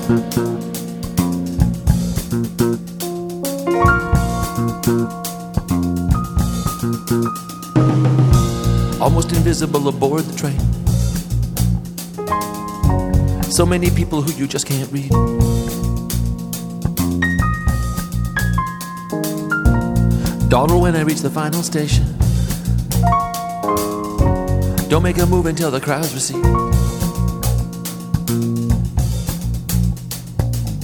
Un album sombre et intime enregistré au studio Fame